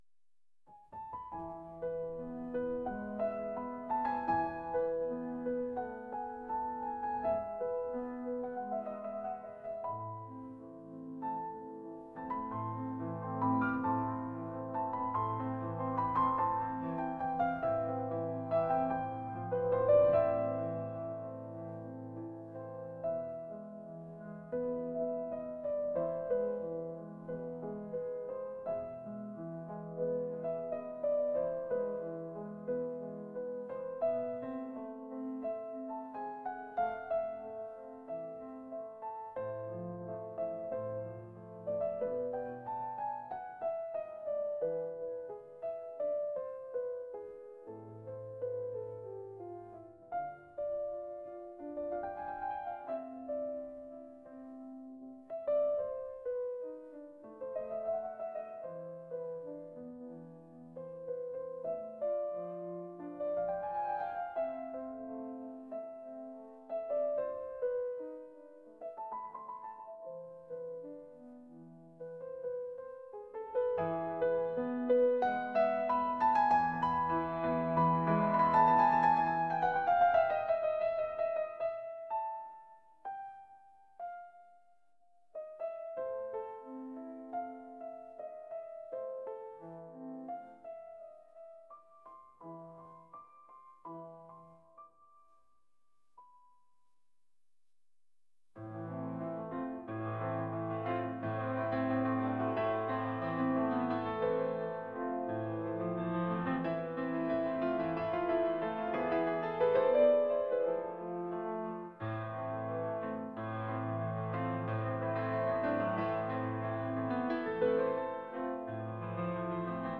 romantic